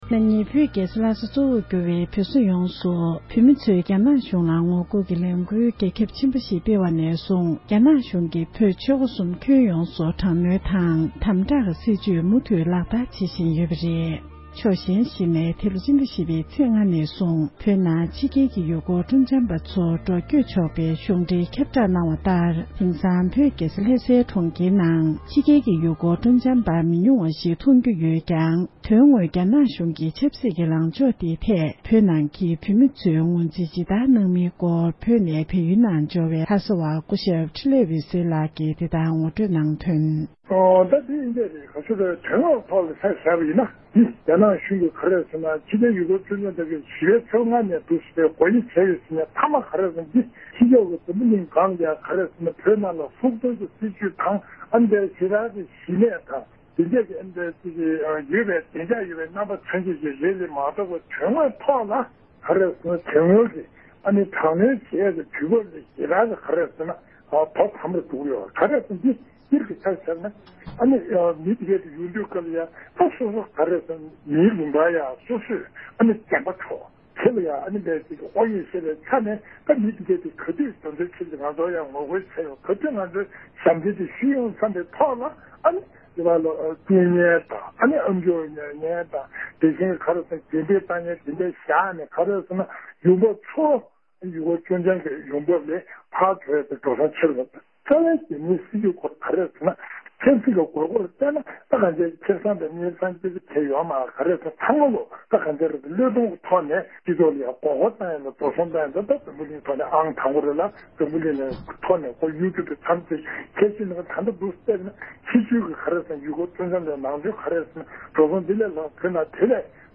བོད་ནང་གི་བོད་མིའི་གནས་སྟངས་སྐོར་གནས་འདྲི་ཞུས་པ།